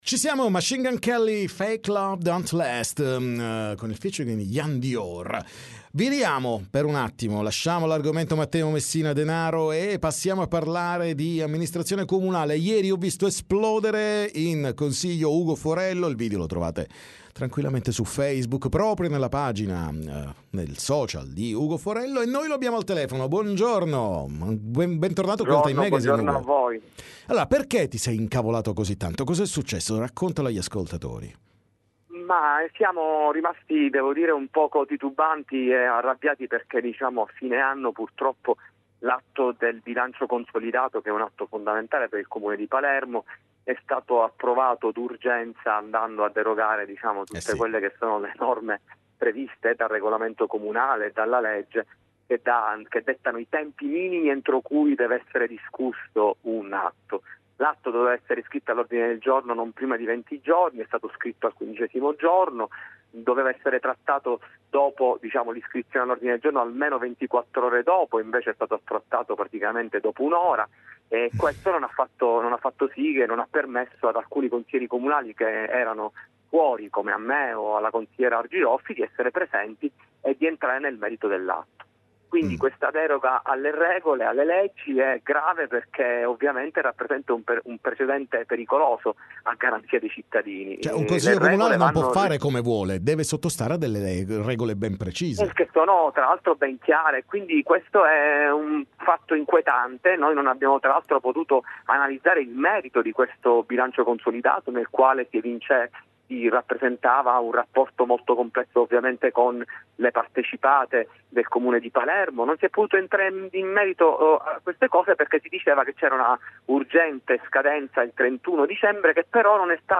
TM Intervista Ugo Forello